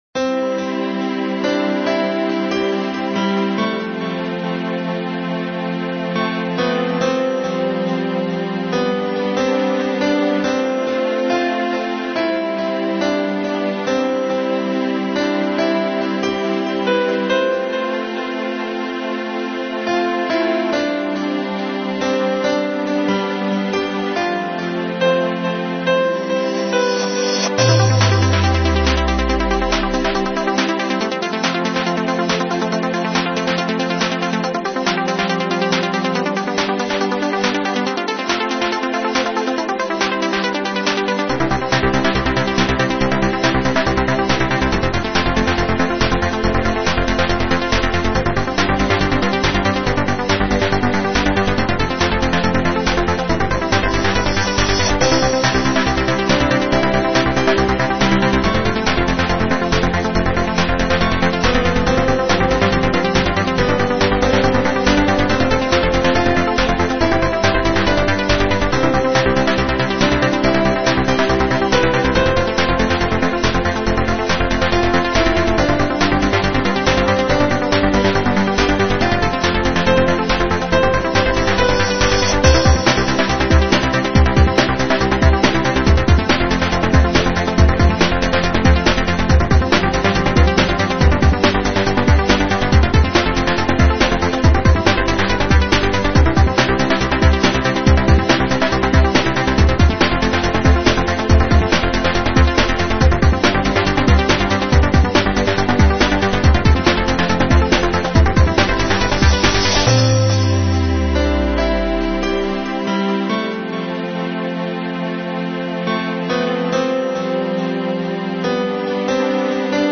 dance/electronic
Techno
Trance